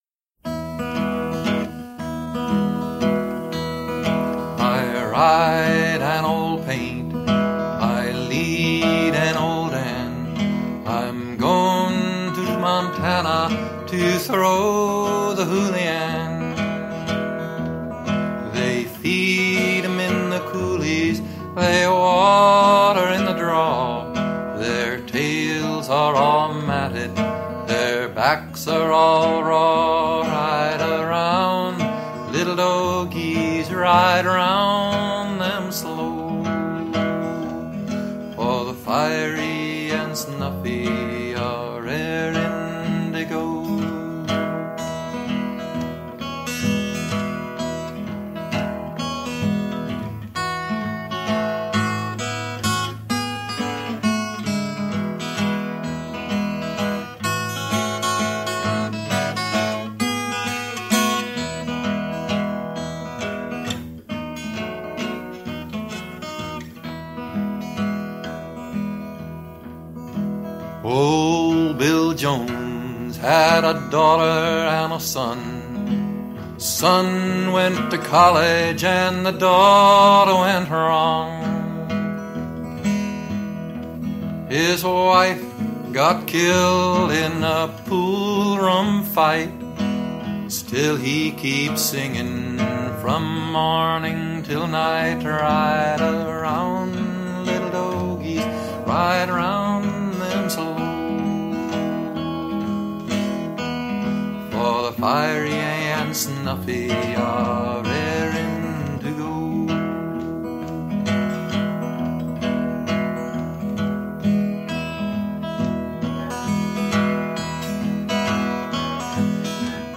Traditional
This song is in 3/4 waltz time.
Listen to Pete Seeger perform "I Ride An Old Paint" (mp3)